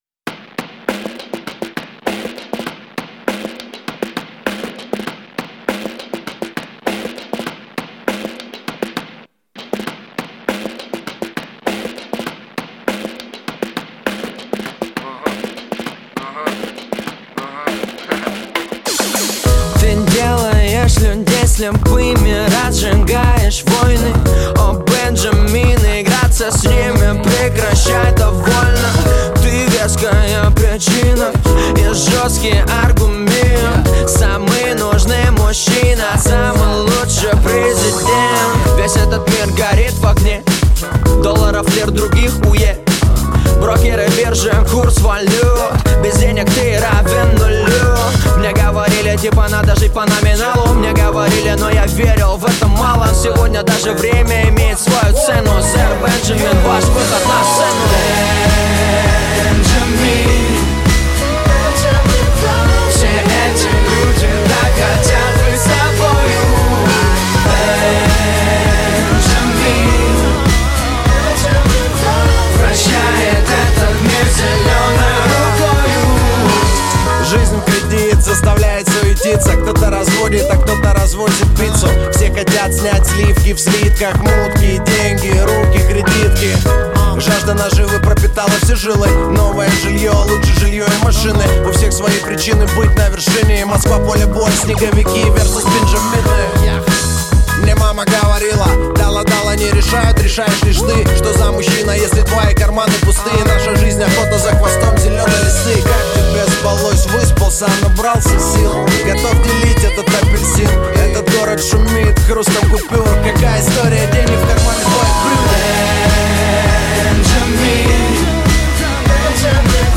Хип-хоп
Жанр: Жанры / Хип-хоп